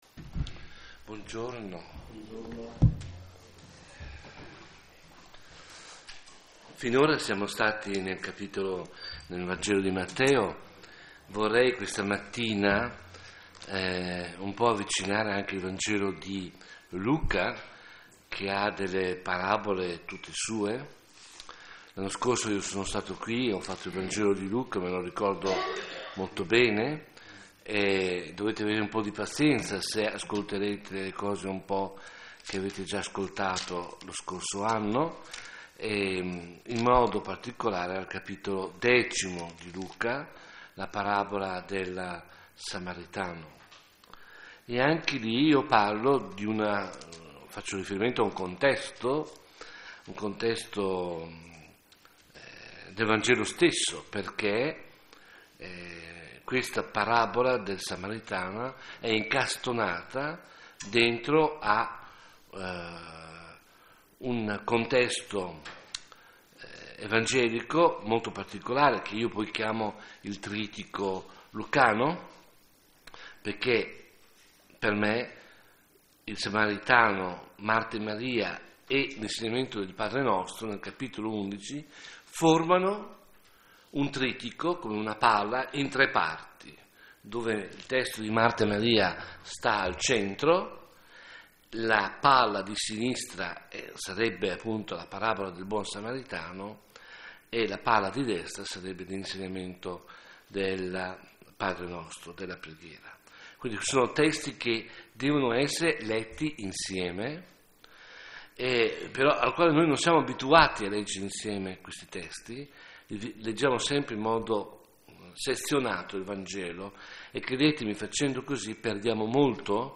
Meditazione
qui a Villa Immacolata